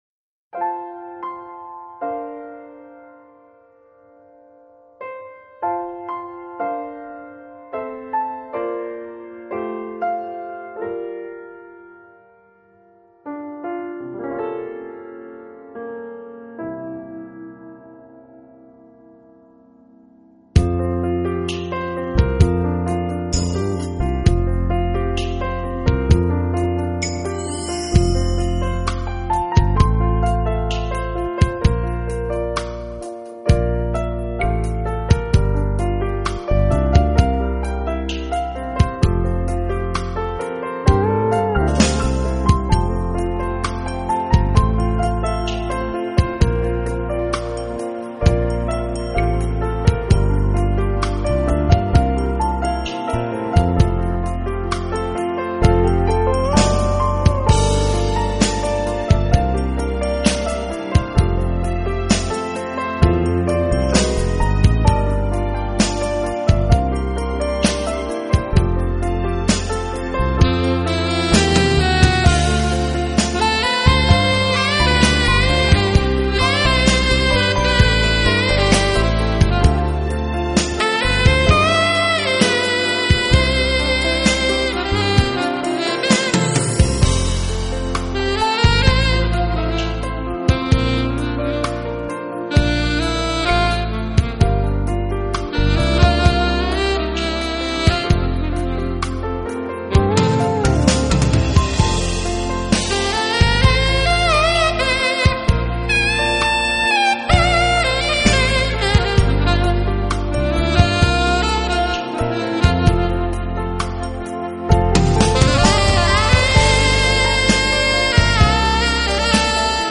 版本：2CD钢琴集